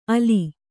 ♪ ali